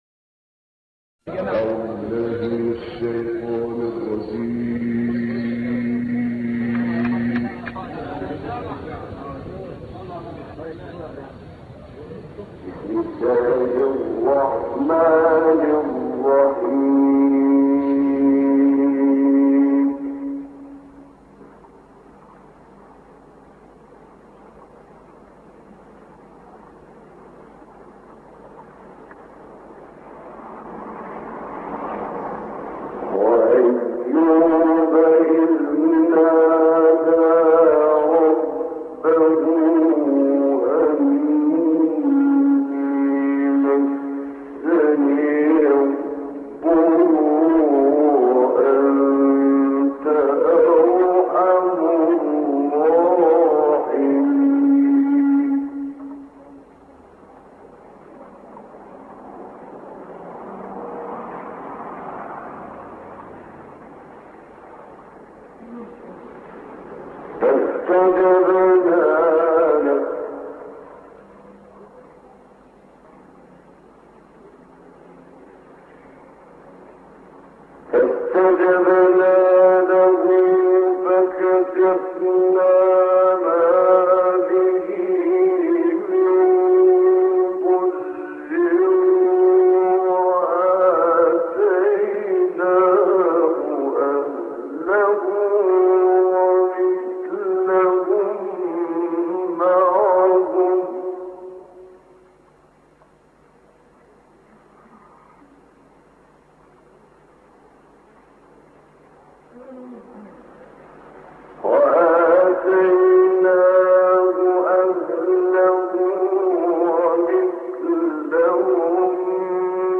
تلاوت سوره‌ انبیاء آیات ۱۱۲-۸۳ با صدای محمد عمران + دانلود
گروه فعالیت‌های قرآنی: خبرگزاری ایکنا با همکاری شبکه رادیویی قرآن، تلاوتی از محمد عمران از سوره‌ انبیاء، آیات ۸۳ - ۱۱۲ را ارائه می‌دهد.